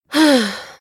huh_sigh
Category: Games   Right: Personal